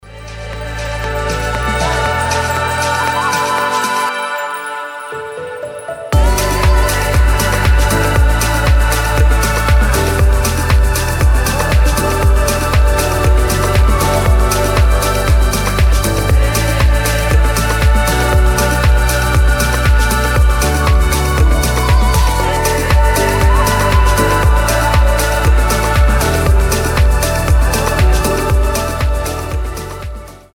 мелодичные
Electronic
Melodic house
organic house
Интересная мелодичная музыка